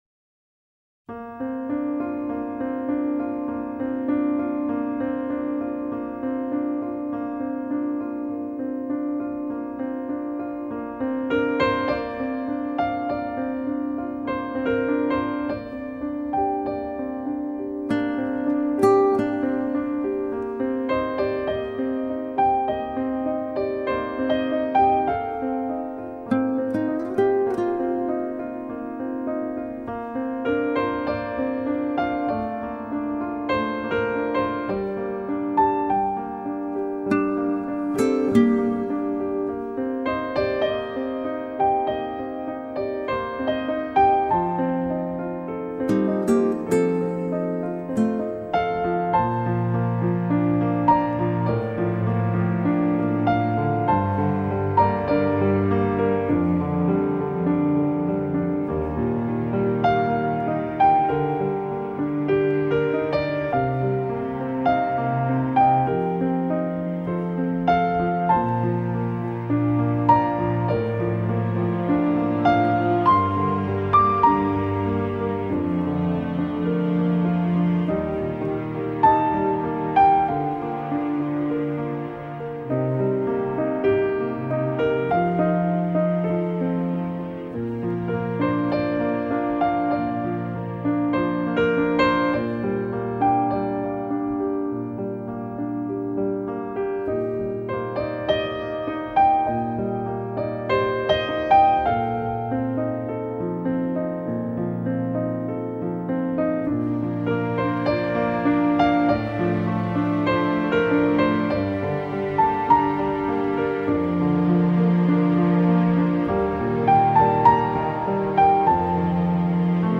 单纯的钢琴，强劲的击键声